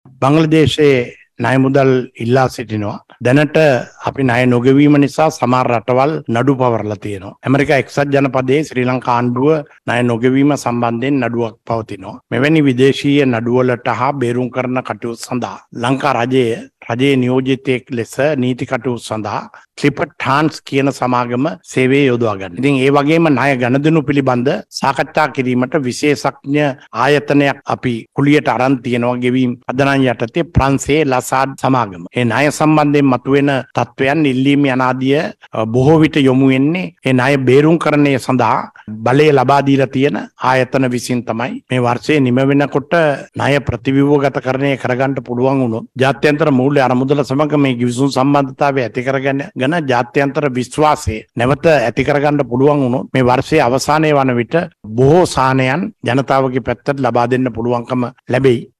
ඔහු මෙම අදහස් දැක්වීම සිදු කළේ අද කොළඹදී කැබිනට් තීරණ දැනුම් දීමේ මාධ්‍ය හමුවට එක් වෙමින්.
මේ ඒ සඳහා පිළිතුරු ලබාදුන් අමාත්‍ය බන්දුල ගුණවර්ධන මහතා.